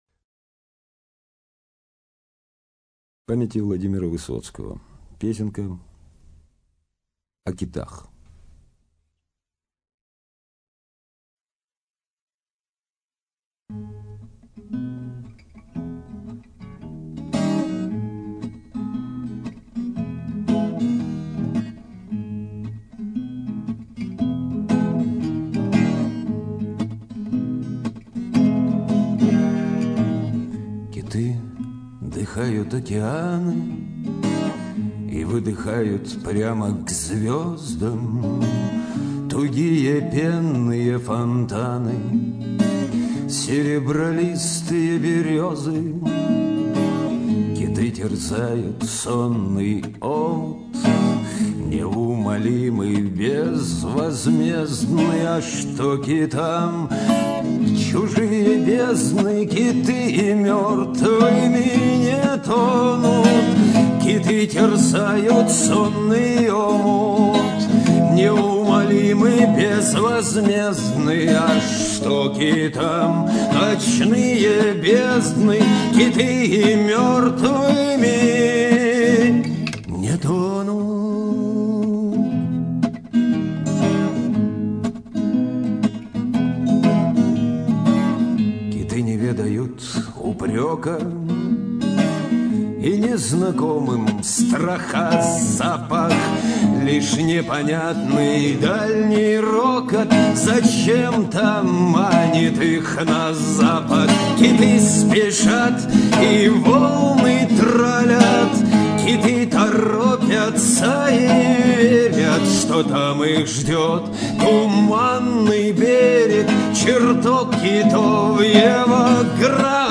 Авторская песня
Играет на 6 и 12-ти струнной гитарах.